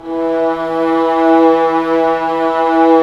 Index of /90_sSampleCDs/Roland LCDP13 String Sections/STR_Violas FX/STR_Vas Sordino